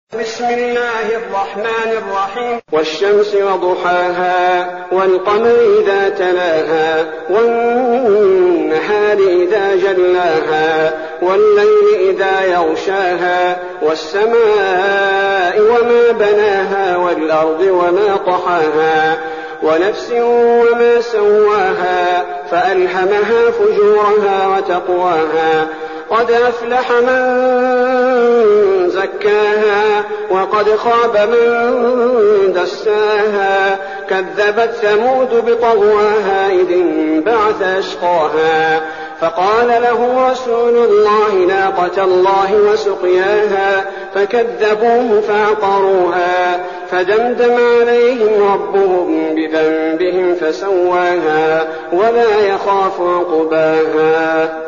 المكان: المسجد النبوي الشيخ: فضيلة الشيخ عبدالباري الثبيتي فضيلة الشيخ عبدالباري الثبيتي الشمس